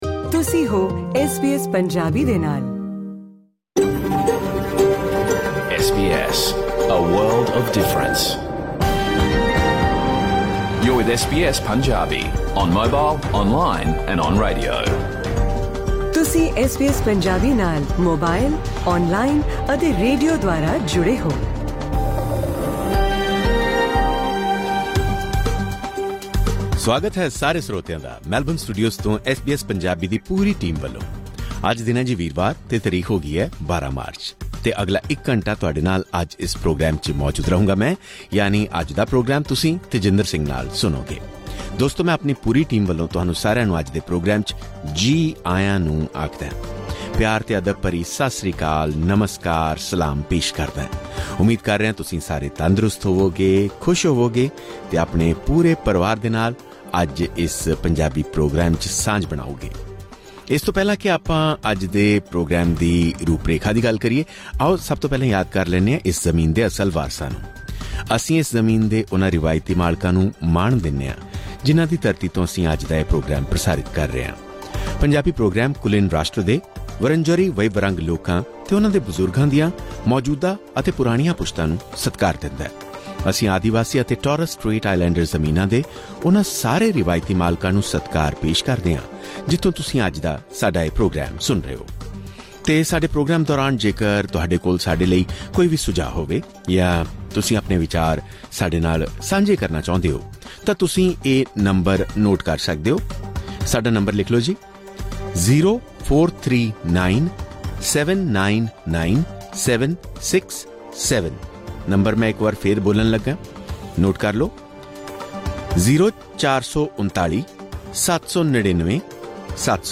ਸੁਣੋ ਐਸ ਬੀ ਐਸ ਪੰਜਾਬੀ ਦਾ ਪੂਰਾ ਰੇਡੀਓ ਪ੍ਰੋਗਰਾਮ
ਇਸ ਐਪੀਸੋਡ ਵਿੱਚ ਮੈਲਬਰਨ ਵਿੱਚ ‘ਮੈਲਬਰਨ ਕੋਬਰਾਜ਼’ ਦੇ ਲਾਂਚ ਨਾਲ ਆਸਟ੍ਰੇਲੀਆ ਦੀ ਹਾਕੀ ਵਨ ਲੀਗ ਵਿੱਚ ਸ਼ੁਰੂ ਹੋਏ ਨਵੇਂ ਦੌਰ ਬਾਰੇ ਜਾਣਕਾਰੀ ਦਿੱਤੀ ਜਾਵੇਗੀ, ਜਿੱਥੇ ਭਾਰਤੀ ਮੂਲ ਦੇ ਲੋਕ ਅਤੇ ਆਸਟ੍ਰੇਲੀਆਈ ਕਮਿਊਨਿਟੀ ਇਕੱਠੇ ਹੋ ਰਹੇ ਹਨ। ਇਸ ਤੋਂ ਇਲਾਵਾ ਅੰਤਰਰਾਸ਼ਟਰੀ ਯਾਤਰਾ ਦੀ ਯੋਜਨਾ ਬਣਾਉਣ ਵਾਲਿਆਂ ਲਈ ਵੀ ਖ਼ਾਸ ਜਾਣਕਾਰੀ ਹੈ ਕਿਉਂਕਿ ਇਸ ਸਾਲ ਹਵਾਈ ਟਿਕਟਾਂ ਦੀ ਕੀਮਤਾਂ ਵਿੱਚ 30 ਫੀਸਦੀ ਤੱਕ ਵਾਧੇ ਦੇ ਅਨੁਮਾਨ ਲਗਾਏ ਜਾ ਰਹੇ ਹਨ। ਆਸਟ੍ਰੇਲੀਆ ਆਉਣ ਸਮੇਂ ਯਾਤਰੀ ਕੀ ਕੁਝ ਨਾਲ ਲਿਆ ਸਕਦੇ ਹਨ ਅਤੇ ਕੀ ਨਹੀਂ, ਇਸ ਬਾਰੇ ਵੀ ਵਿਸਥਾਰ ਨਾਲ ਚਰਚਾ ਕੀਤੀ ਜਾਵੇਗੀ। ਨਾਲ ਹੀ ਪੰਜਾਬੀ ਗੱਲਬਾਤਾਂ ਅਤੇ ਸੁਹਣੇ ਗੀਤਾਂ ਦਾ ਆਨੰਦ ਲੈਣ ਲਈ ਇਹ ਪੌਡਕਾਸਟ ਸੁਣੋ।